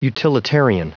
Prononciation du mot utilitarian en anglais (fichier audio)
Prononciation du mot : utilitarian